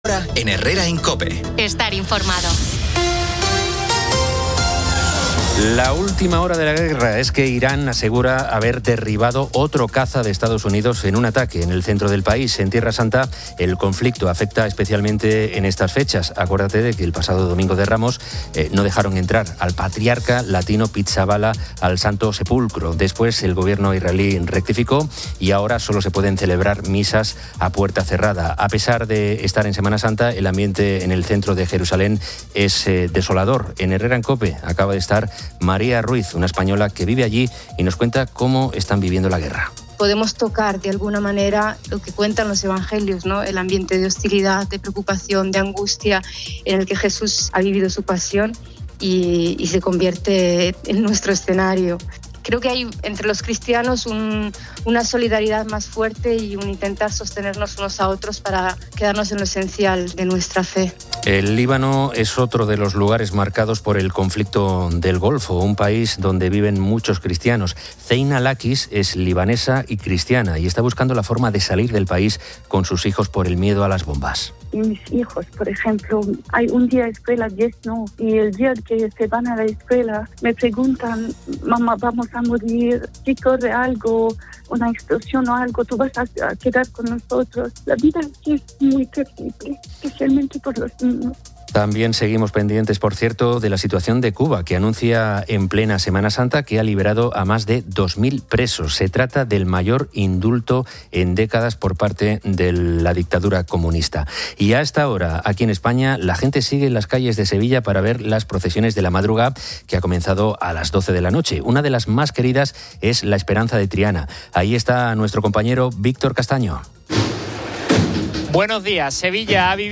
Oyentes comparten diversas colecciones: rosarios, revistas, botes de cerveza, audífonos, faros antiniebla, pins, colecciones de Snoopy, pasadores militares, imanes, tarjetas de teléfono y belenes.